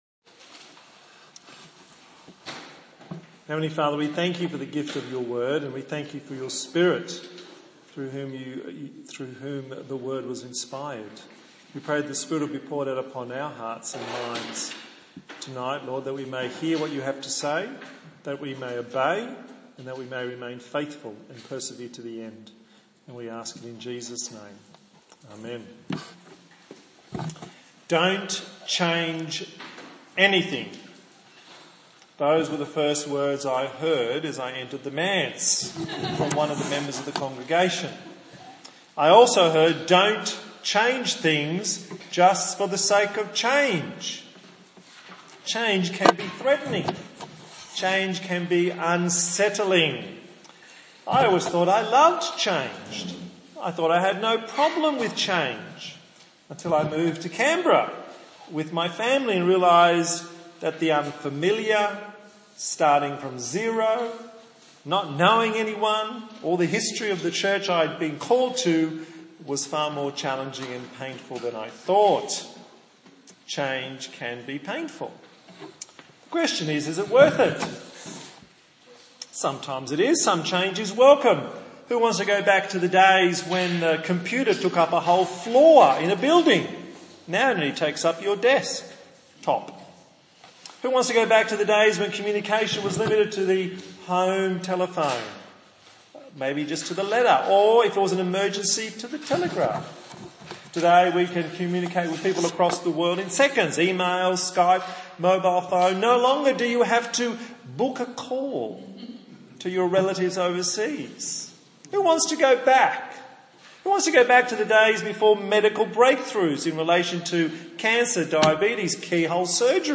A sermon in the series on the book of Hebrews